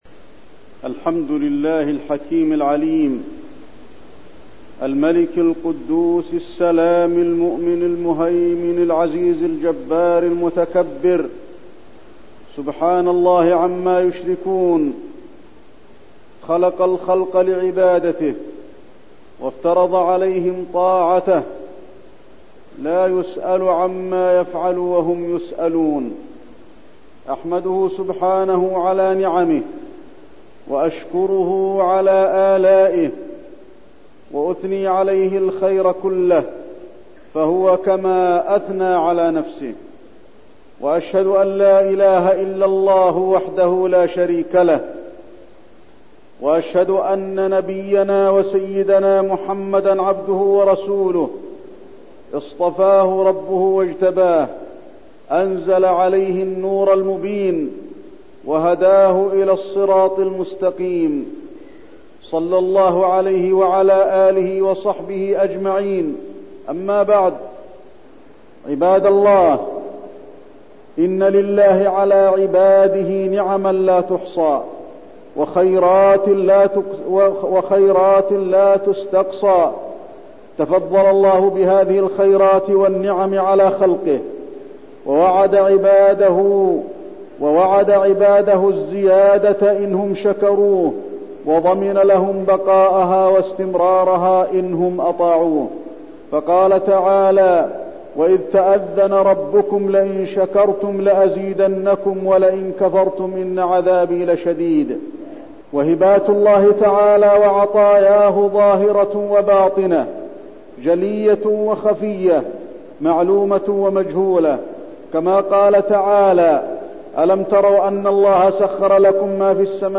تاريخ النشر ٨ ذو القعدة ١٤١٠ هـ المكان: المسجد النبوي الشيخ: فضيلة الشيخ د. علي بن عبدالرحمن الحذيفي فضيلة الشيخ د. علي بن عبدالرحمن الحذيفي نعم الله تعالى